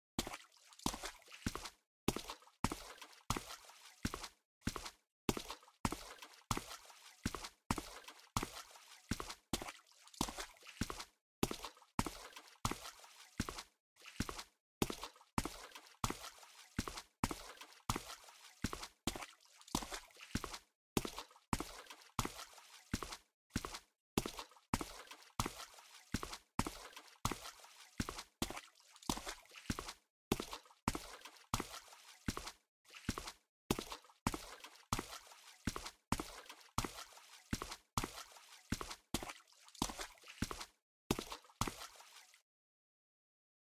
Звуки лужи
Шаги по мокрому асфальту среди луж